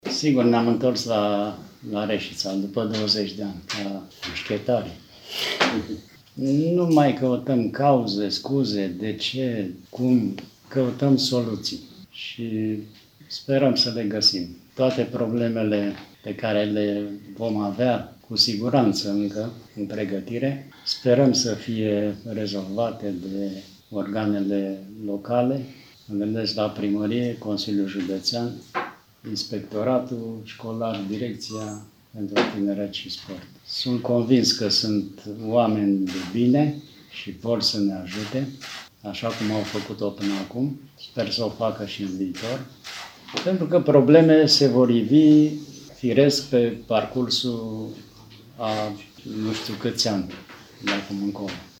Despre acest nou proiect a vorbit și vicepreședintele Federației Române de Gimnastică, Dan Grecu, care a subliniat că dorește implicarea intensă a oficialităților și a asigurat că se caută soluții pentru a aduce gimnastica din românia acolo unde a fost odată: